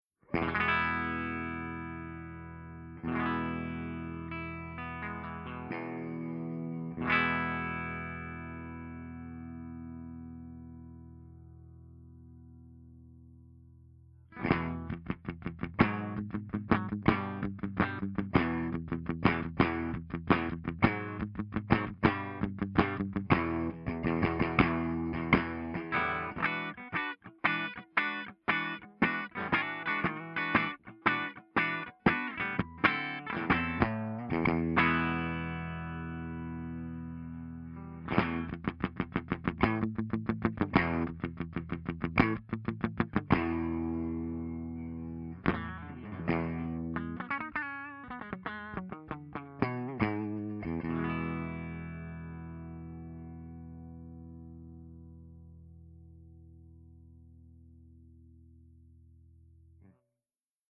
053_FENDERTWIN_STANDARD_HB.mp3